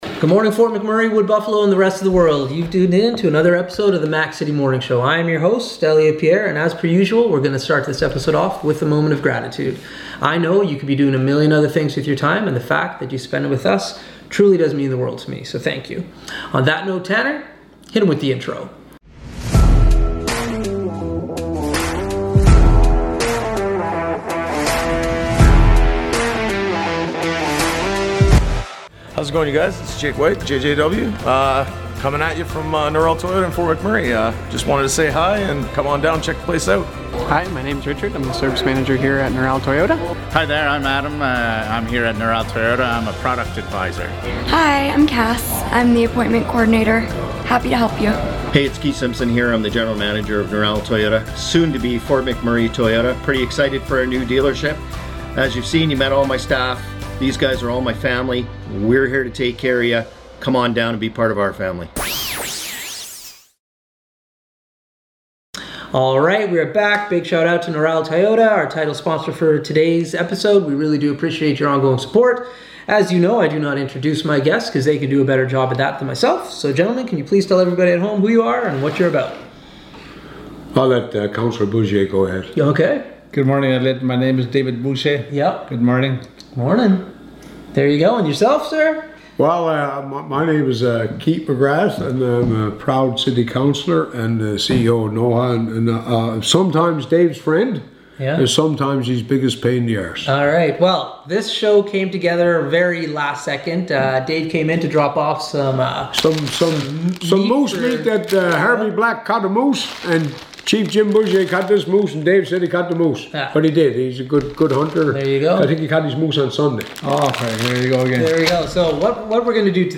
Dave Bouchier and Keith McGrath, Fort McMurray Councilors are on the show today!